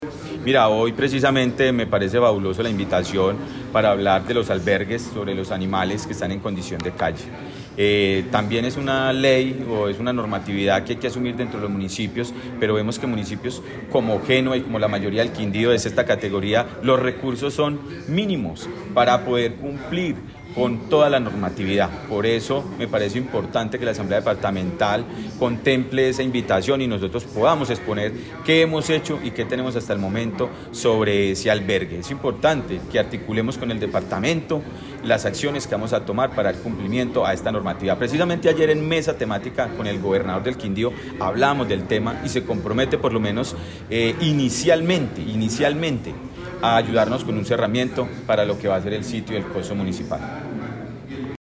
Audio: Diego Fernando Sicua Galvis, alcalde Génova
Diego_Fernando_Sicua_Galvis_Alcalde_de_Ge_nova_AUDIO.01.mp3